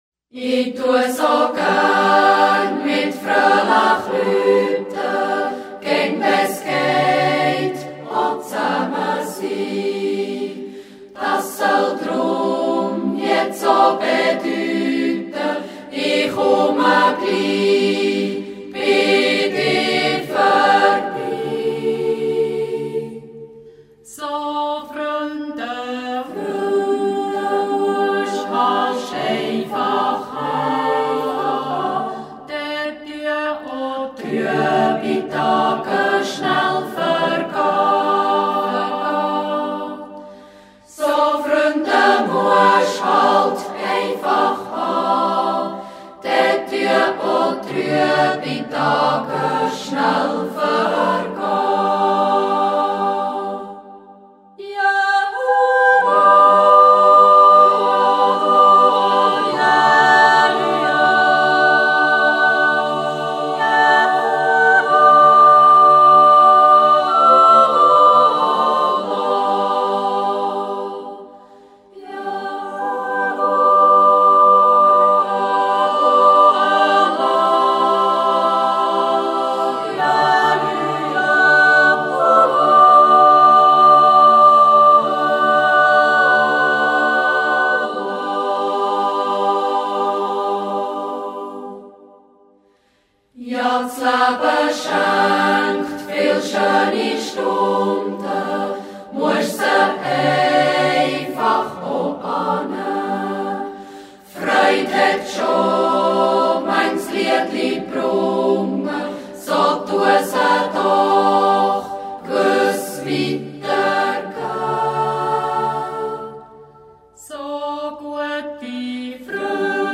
Natural yodel, yodel songs and Swiss folk dances.
Guests: Jodlerklub St. Stephan, Jodlergruppe Simmefall and Schwyzerörgeliquartett Abebärg.
Yodel song.
JG Echo vom Flösch